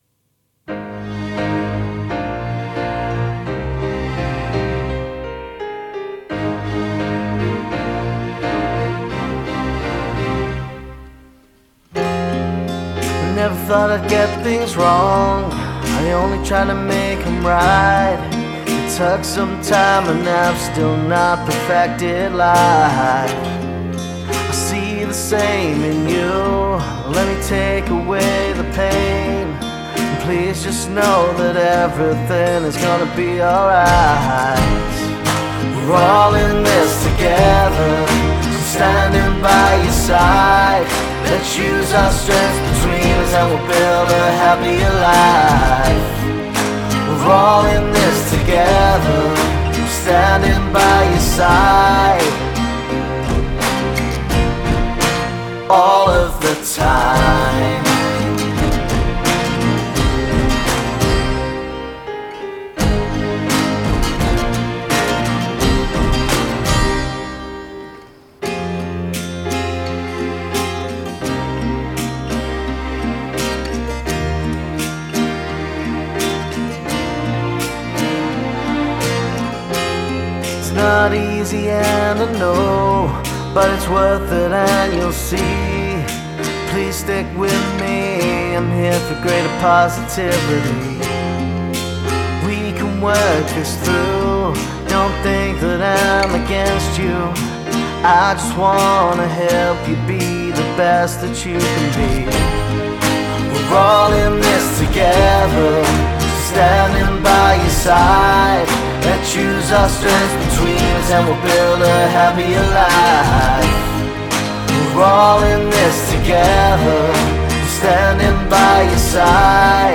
Genre: singersongwriter, pop.